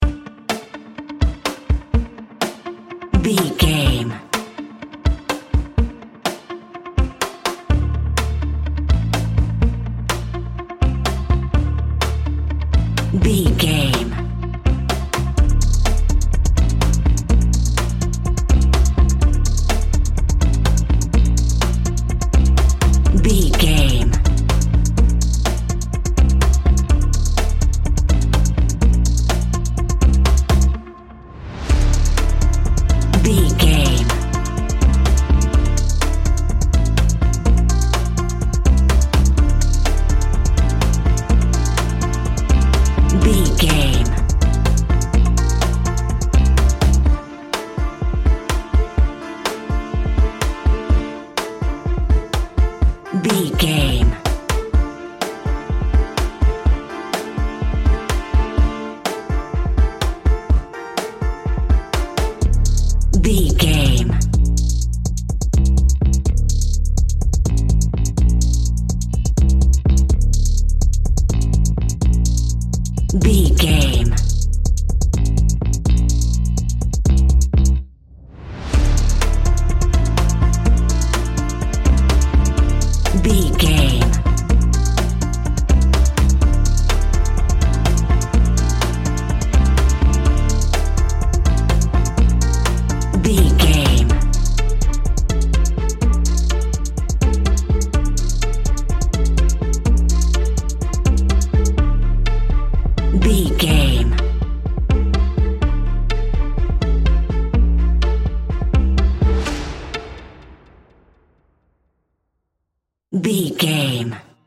Aeolian/Minor
D
intense
relaxed
suspense
tension